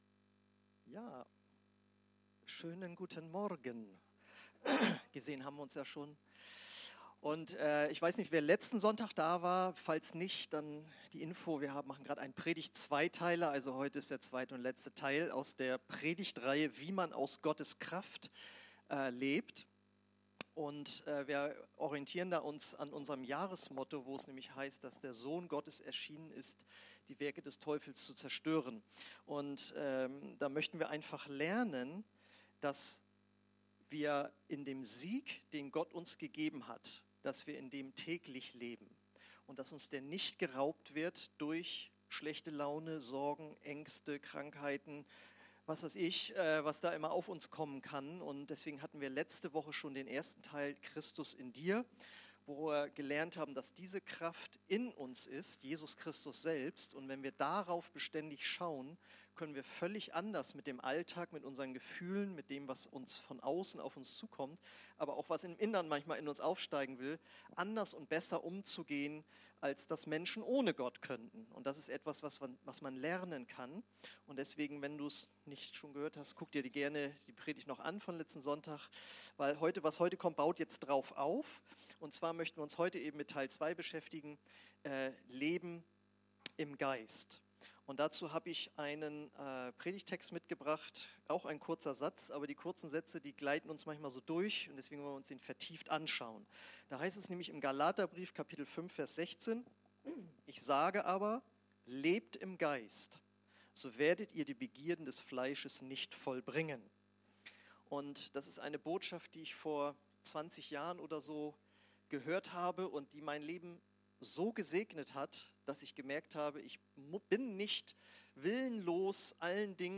Predigten – OASIS Kirche